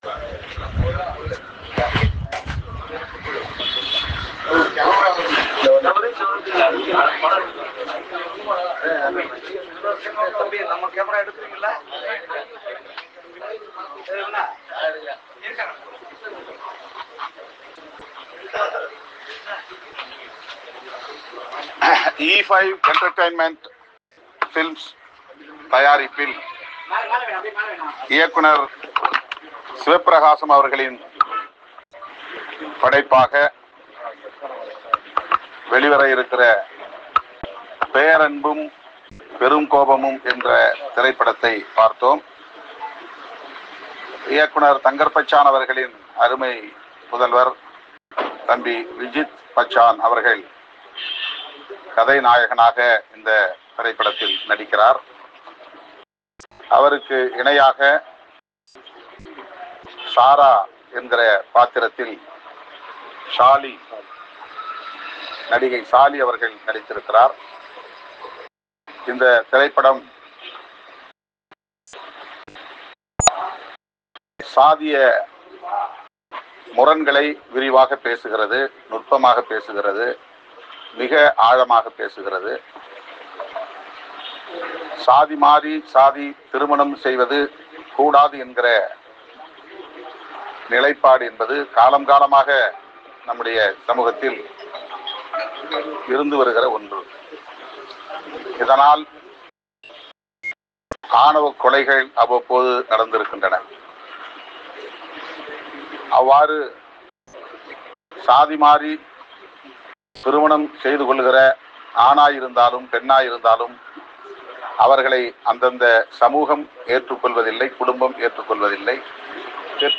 “பேரன்பும் பெருங்கோபமும்” படத்தை விடுதலை சிறுத்தைகள் கட்சி தலைவர் தொல்.திருமாவளவன் பார்த்துவிட்டு அளித்த பேட்டி ஆடியோ பேச்சு.